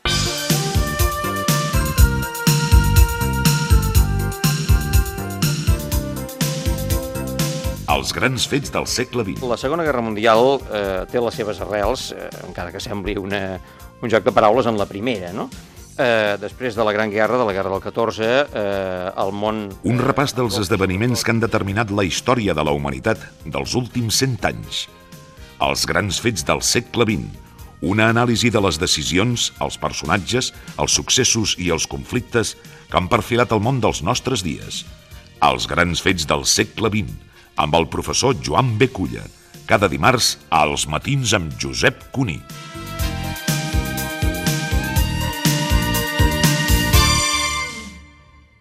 Promoció de la secció "Els grans fets del segle XX" amb Joan B. Culla.
Programa presentat per Josep Cuní.
Fragment extret de l'arxiu sonor de COM Ràdio.